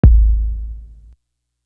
Big Boy Kick.wav